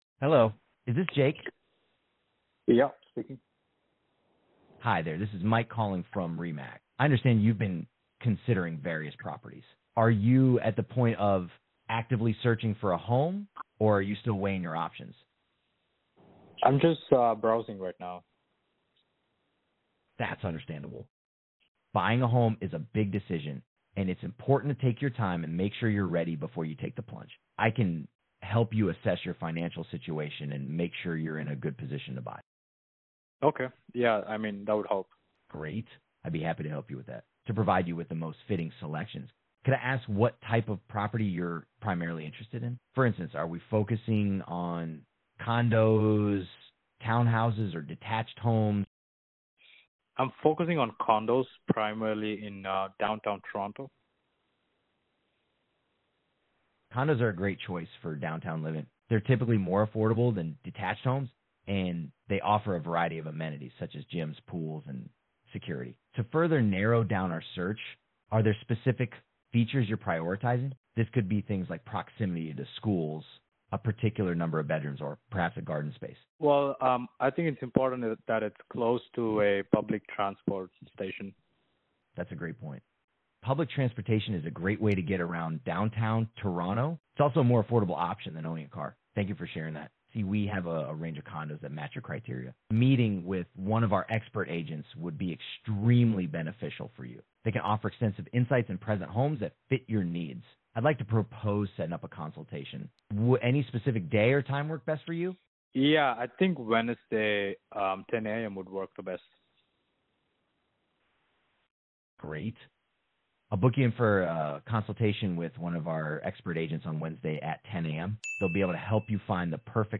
Experience Ultra-Realistic AI Voice Agent
sample-AI-real-estate-call.mp3